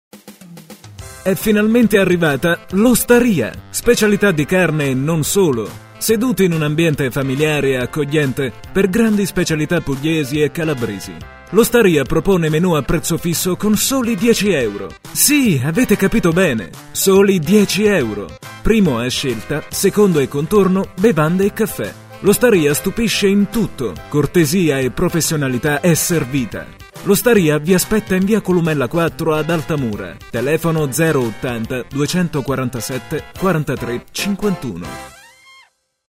Sprechprobe: Industrie (Muttersprache):
Warm italian voice for documntary, e -learning, audioguide, audiobook etc.